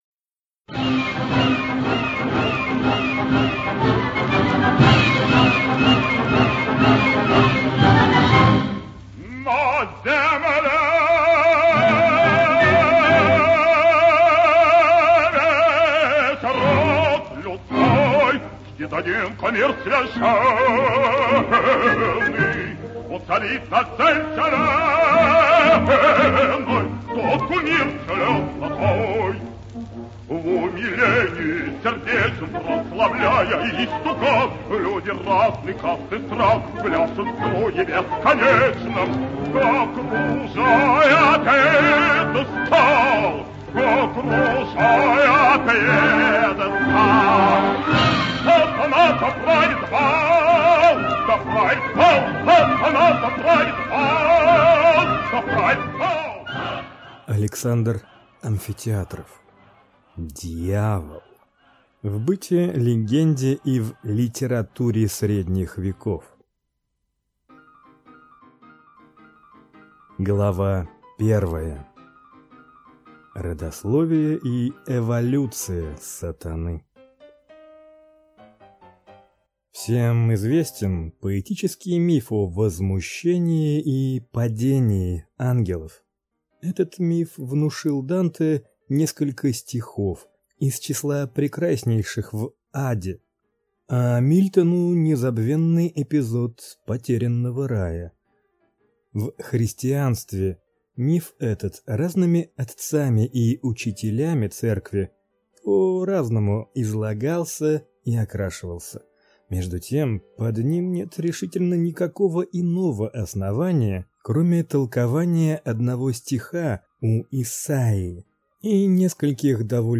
Аудиокнига Дьявол | Библиотека аудиокниг